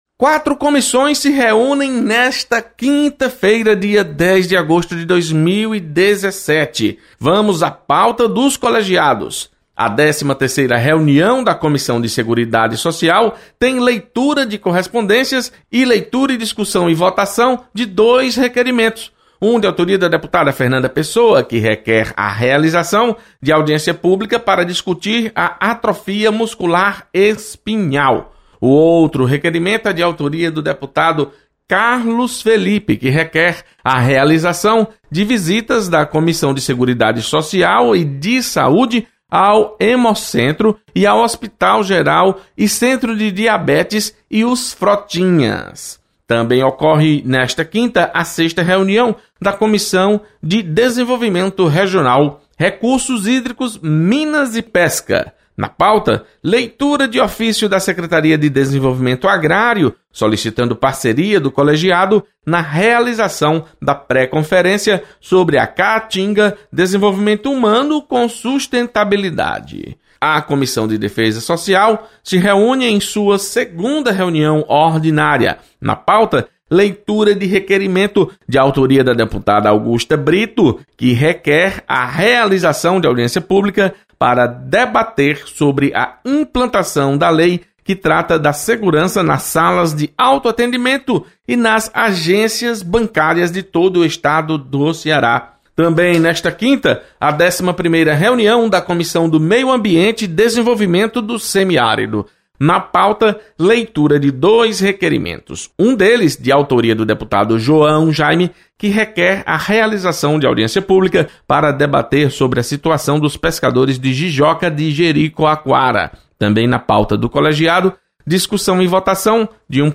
Acompanhe a pauta das reuniões ordinárias das comissões técnicas permanentes, nesta quinta-feira. Repórter